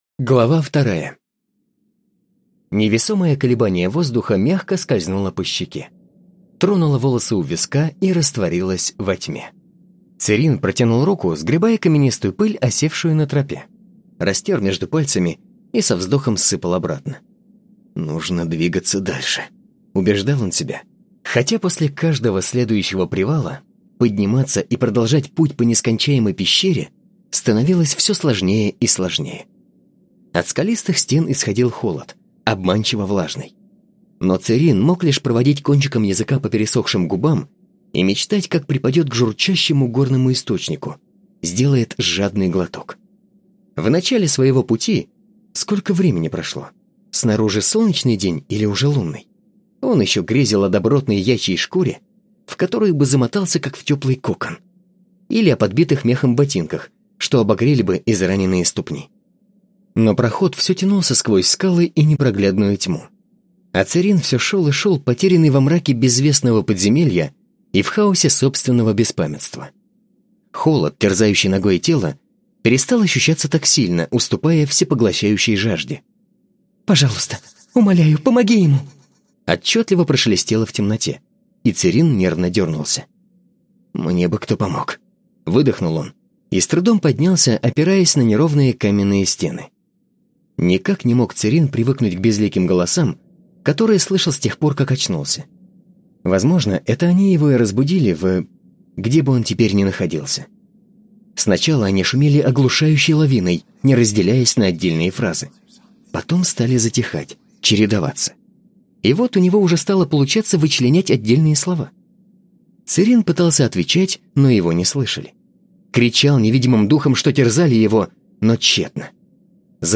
Диктор, актёр озвучивания, у микрофона более пяти лет.
Молодой, энергичный, если нужно романтичный голос.
Тракт: Микрофон SE Electronics X1 S, звуковая карта Arturia MiniFuse 2, акустически оборудованное помещение (домашняя студия).